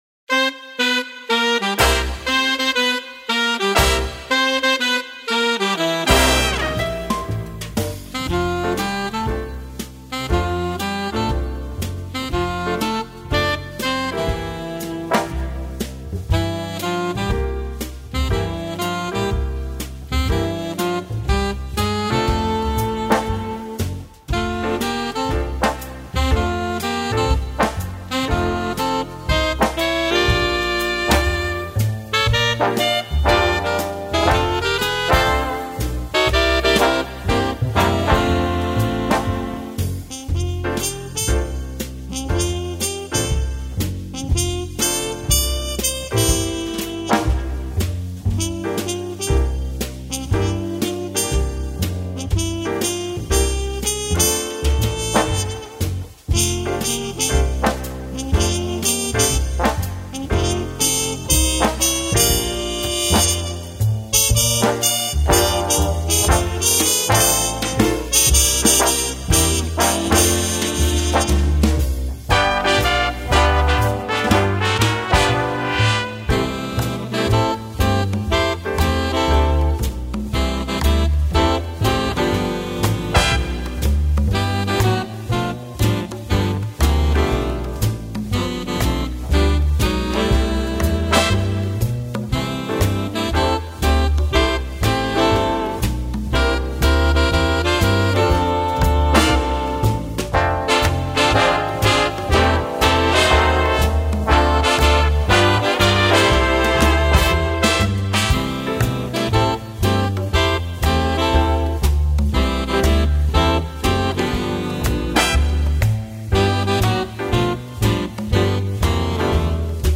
Foxtrott